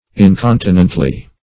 incontinently - definition of incontinently - synonyms, pronunciation, spelling from Free Dictionary
Incontinently \In*con"ti*nent*ly\, adv.